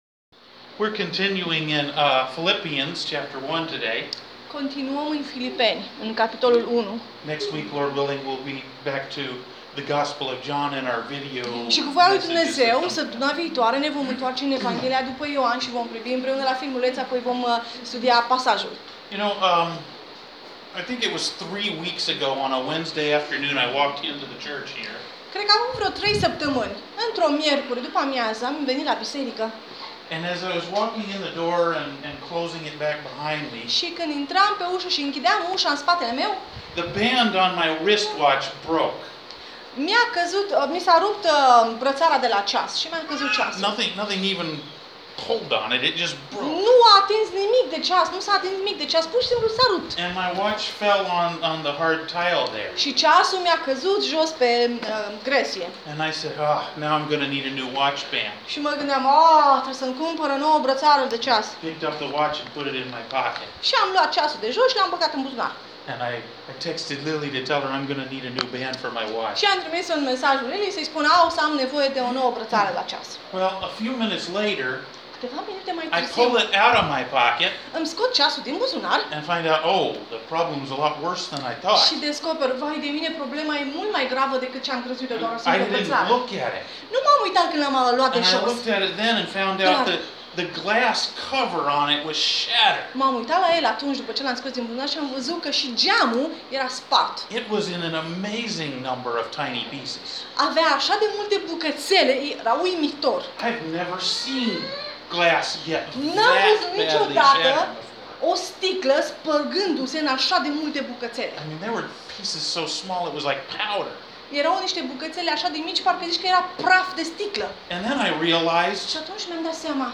Mesaj audio mp3 – Filipeni 1:7-18